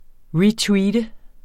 Udtale [ ˈɹiːˌtwiːdə ]